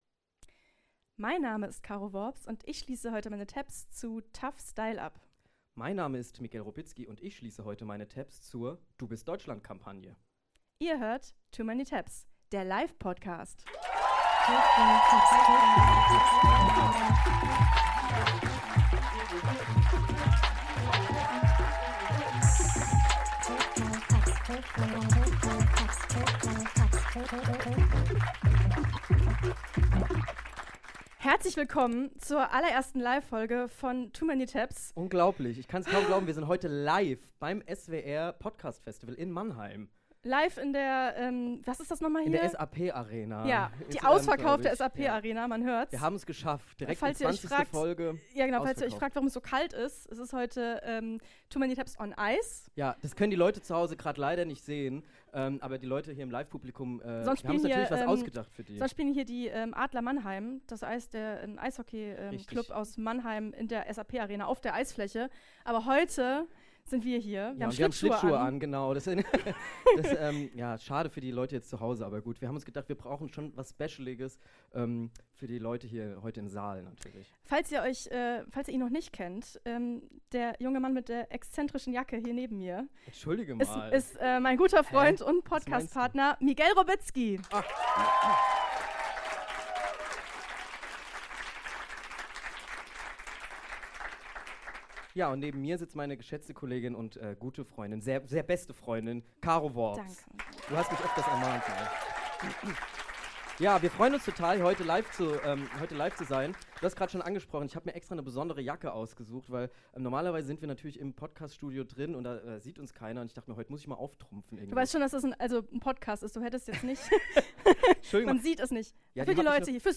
Live-Premiere bei tmt!
Danke an das fantastische Mannheimer Publikum